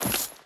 Footsteps / Water / Water Chain Land.wav
Water Chain Land.wav